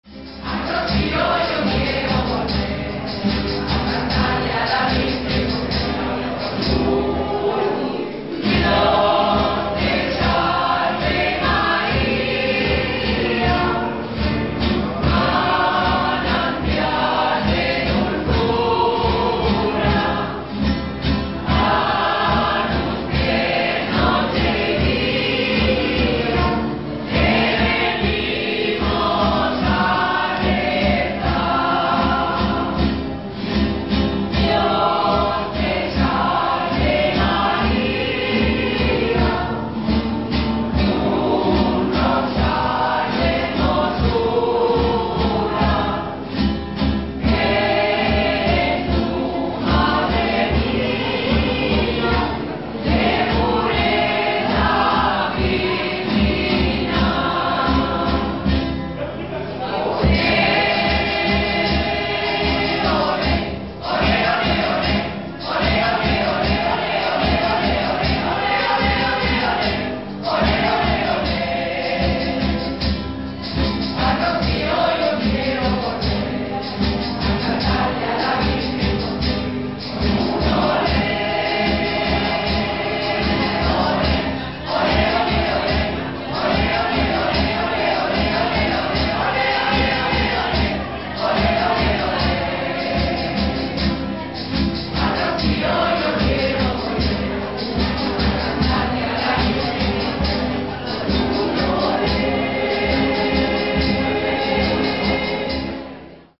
Procesi�n en honor a la Virgen de las Vi�as 2006